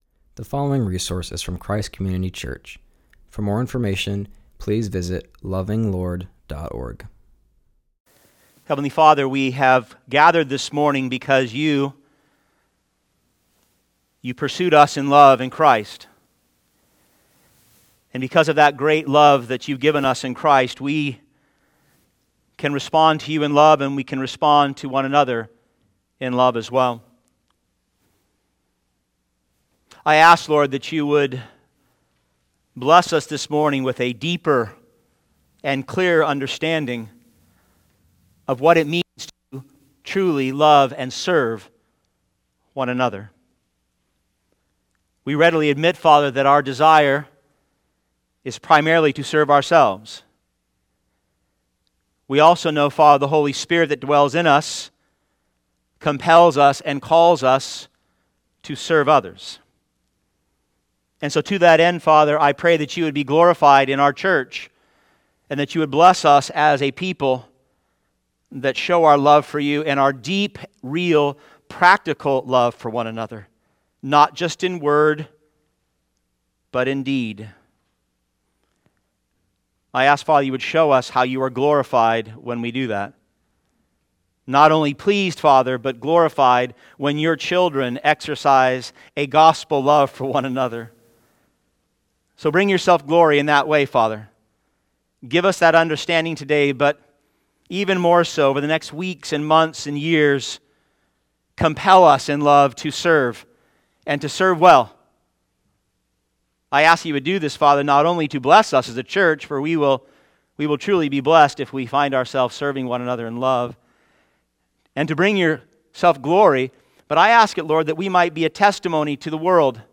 continues our series by preaching on Galatians 5:13-14.